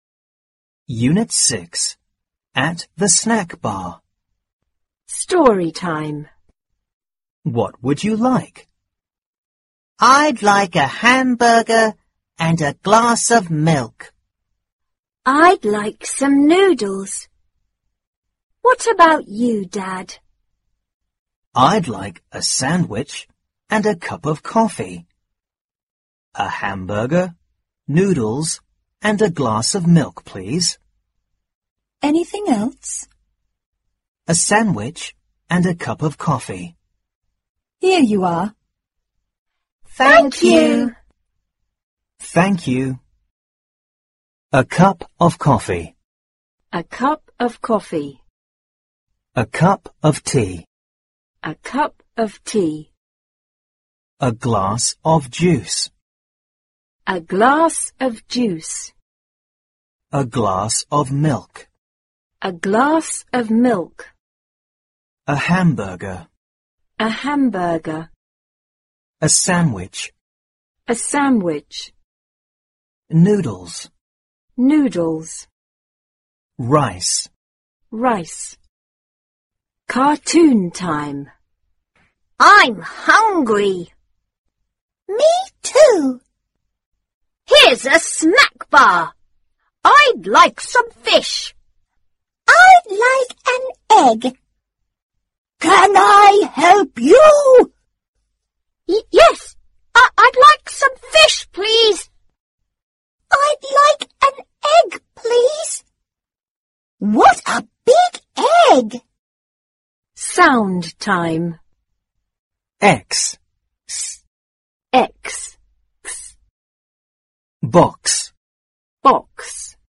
四年级英语上Unit 6 课文.mp3